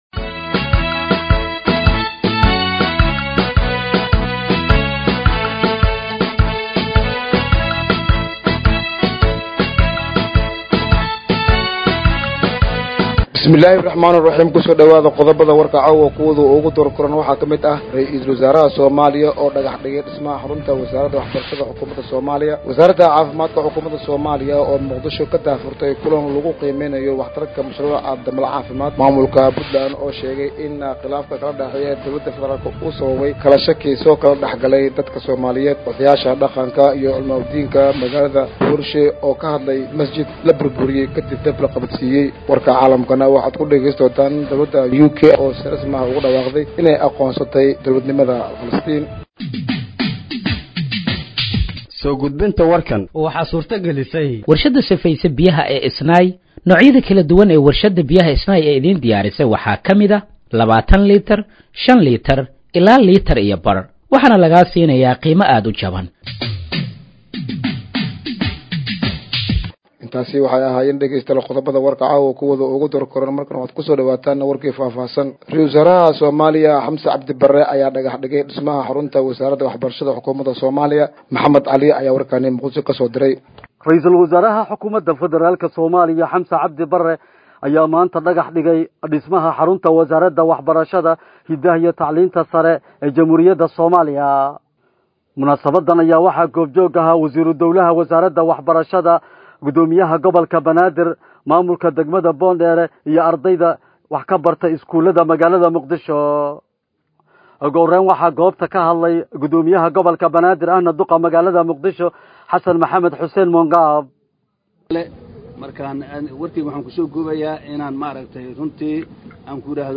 Dhageeyso Warka Habeenimo ee Radiojowhar 21/09/2025